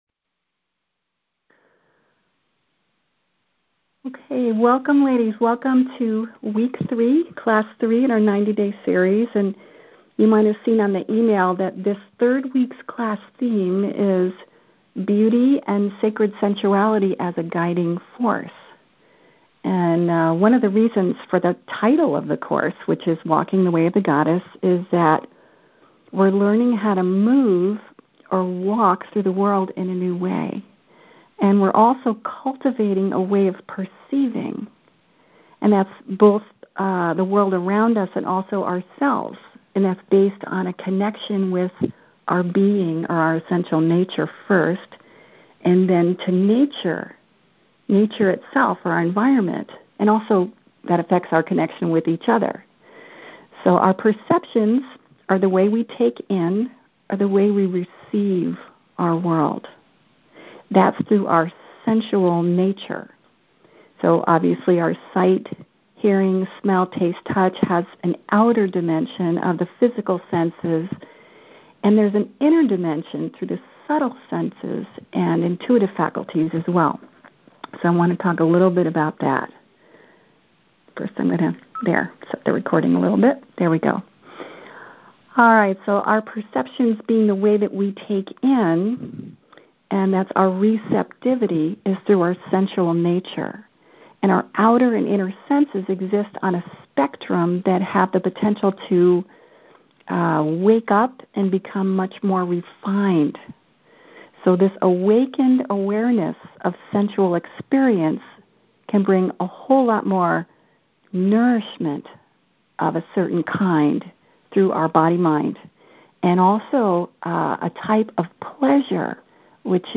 Click the play button below to replay our third class or "right-click" and click "Save As" on the link underneath to download the file for your library.